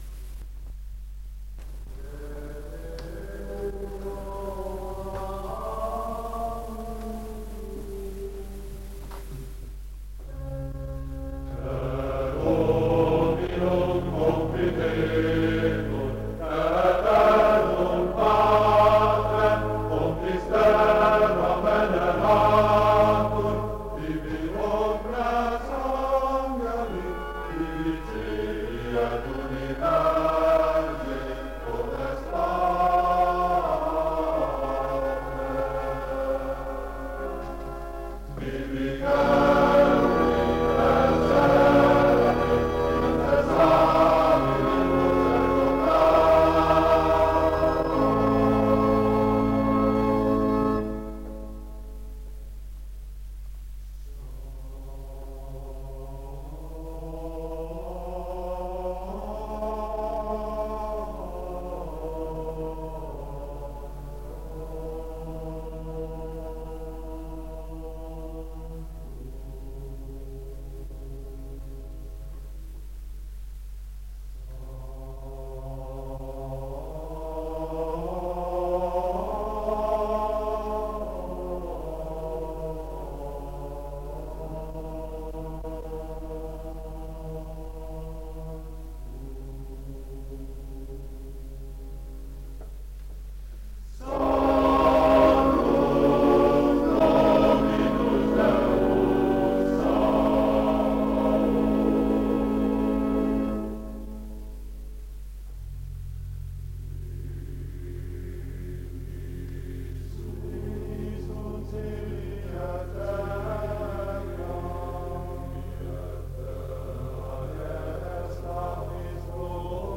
Az esztergomi szeminárium kórusa
Ünnepi alkalmakkor ez a kórus szolgált a Bazilikában a szentmisék alatt. Ezekből a gyönyörű katolikus énekekből található az alábbiakban néhány régi hangfelvétel. A felvétel az esztergomi Bazilikában készült.